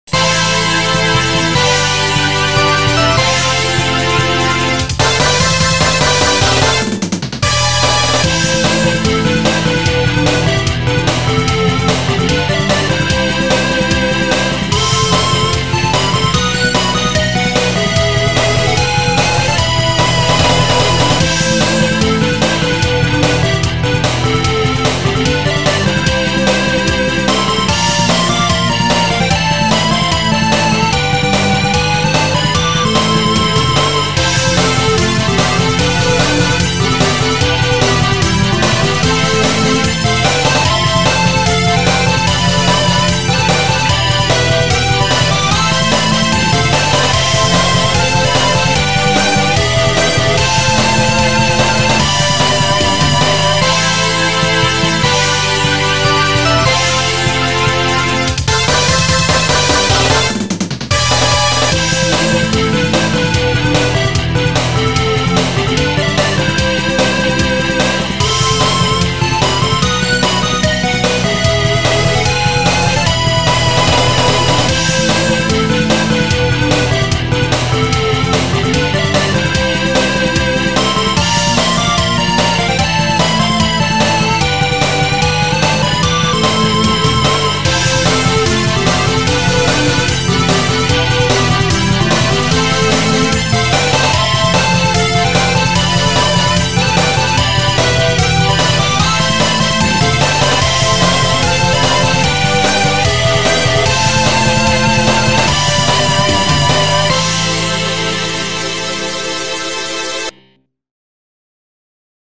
ゲーム音楽アレンジ集